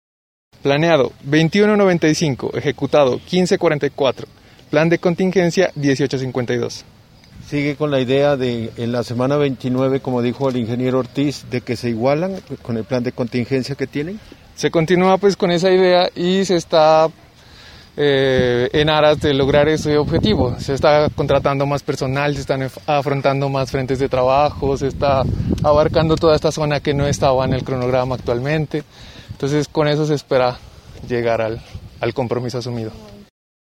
Los integrantes de las veedurías ciudadanas del proyecto de construcción de la plaza de mercado de Sandoná y dos funcionarias de la Administración Municipal visitaron la obra este martes en la tarde.